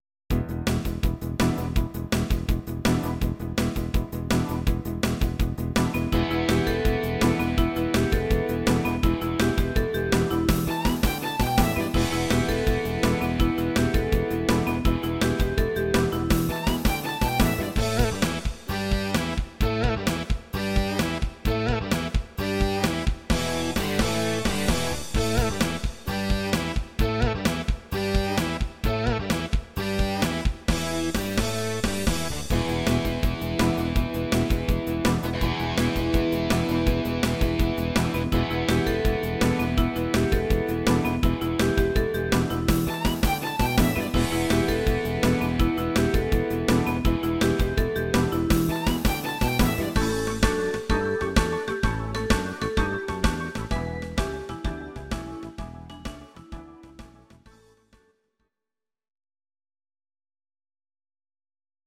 These are MP3 versions of our MIDI file catalogue.
Please note: no vocals and no karaoke included.
Your-Mix: Rock (2958)